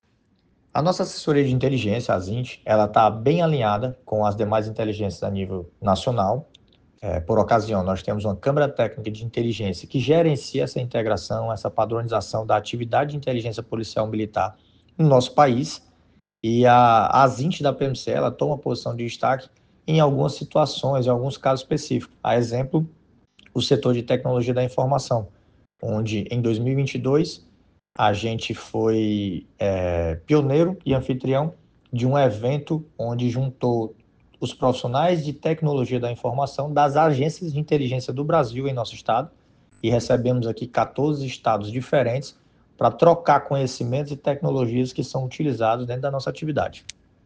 Em entrevista por áudio à Assessoria de Comunicação da PMCE